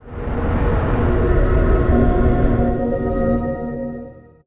Windows X Startup.wav